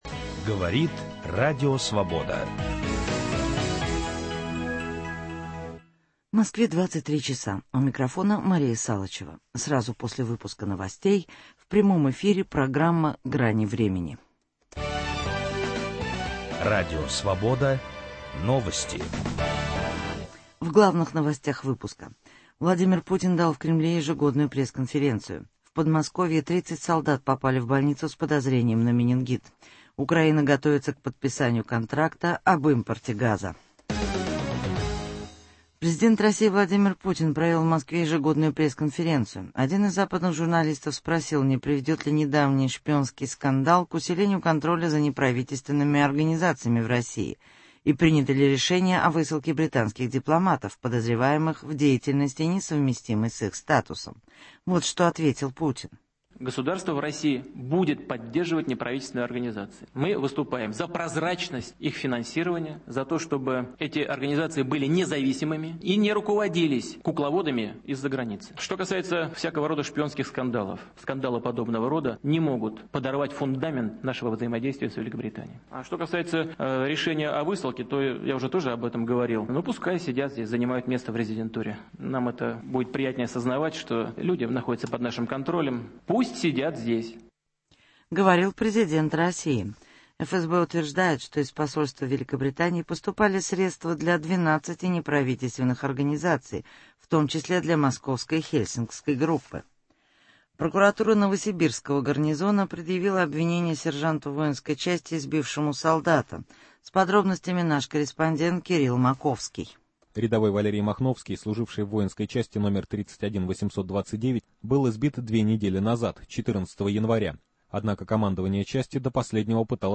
Ежегодная (т.н. "большая") пресс-конференция президента России Владимира Путина. Гость: Олег Попцов, писатель, бывший руководитель телеканала ТВЦ